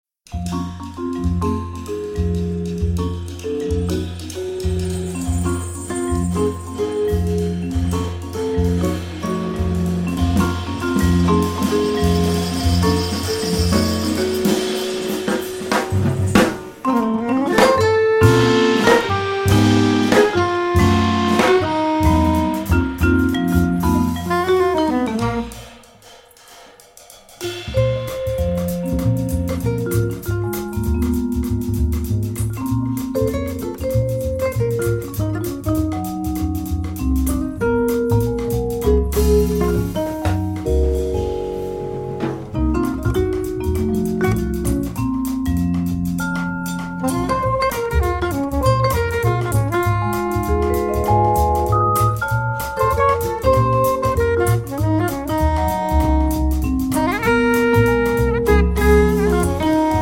vibraphone, percussion, piano